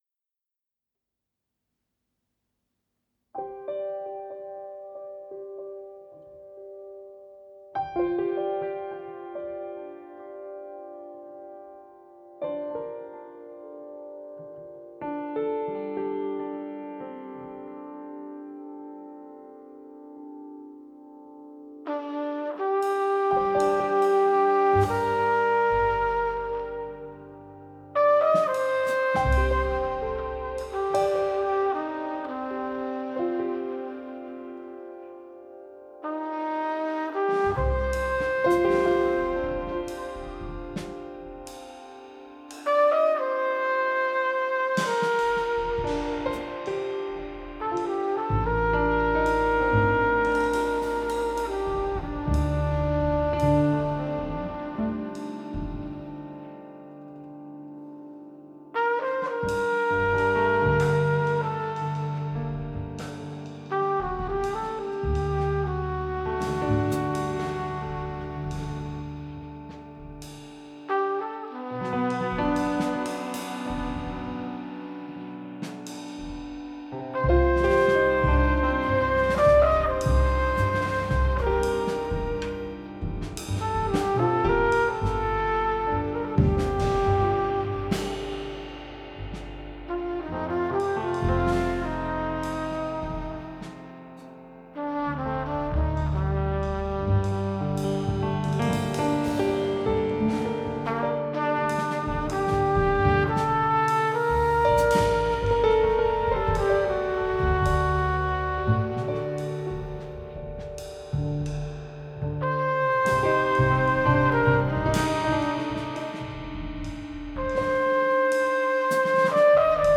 hypnotisant